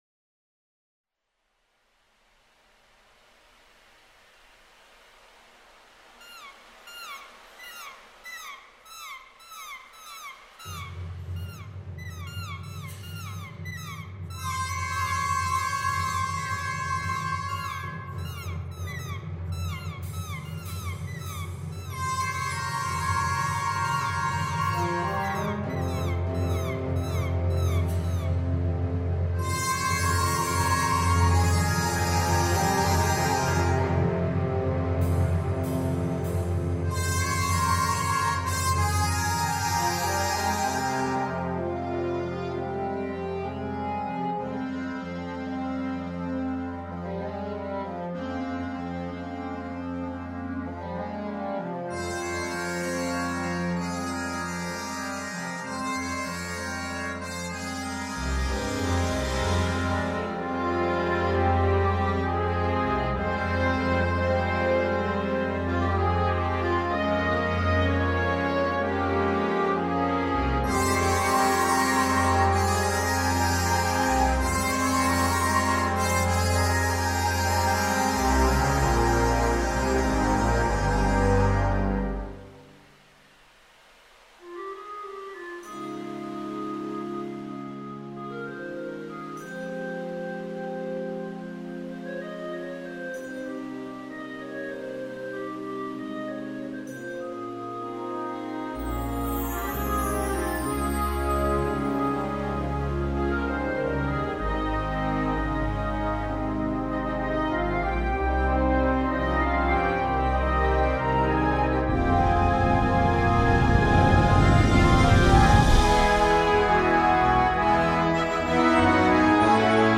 Partitions pour orchestre d'harmonie.
• View File Orchestre d'Harmonie